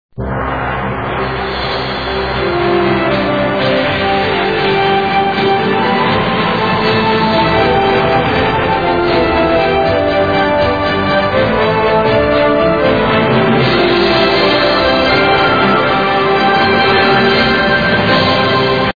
Here it is the 11pm news bulletin.